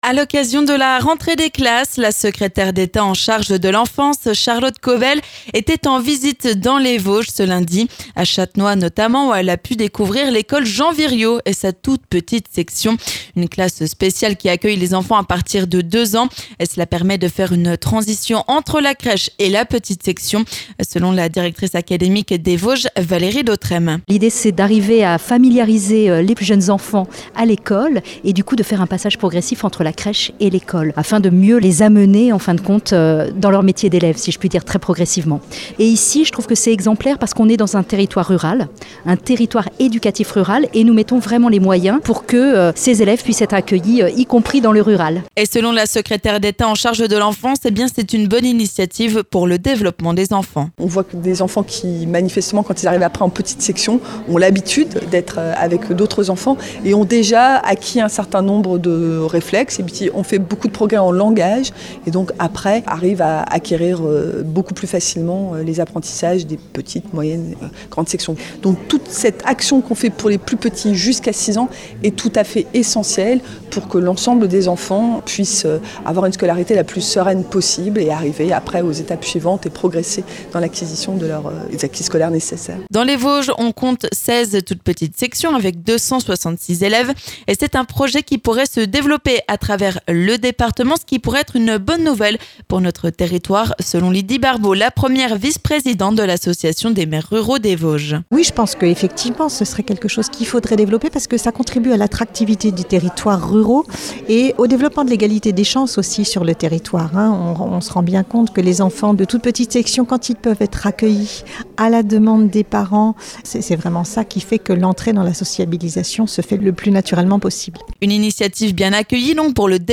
On en parle sur Vosges FM avec Charlotte Caubel, secrétaire d'Etat en charge de l'Enfance.